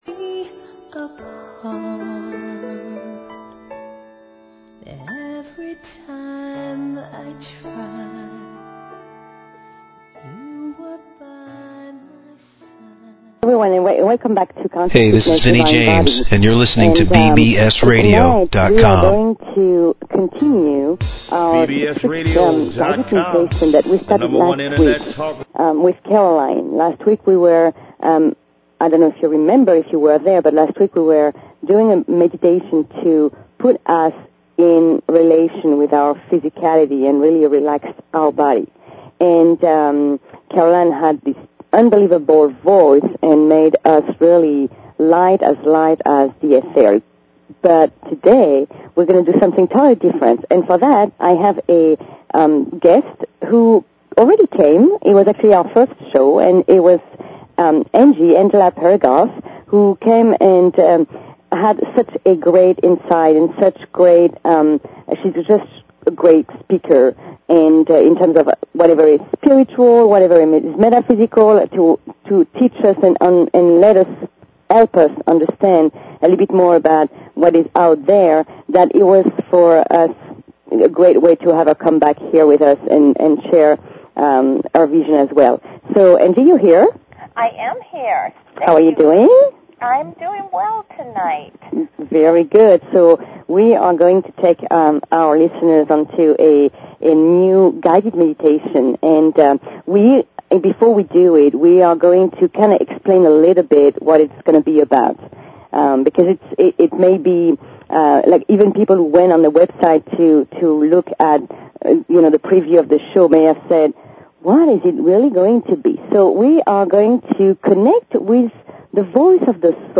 Talk Show Episode, Audio Podcast, Conscious_Fitness and Courtesy of BBS Radio on , show guests , about , categorized as